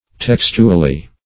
Search Result for " textually" : The Collaborative International Dictionary of English v.0.48: Textually \Tex"tu*al*ly\, adv.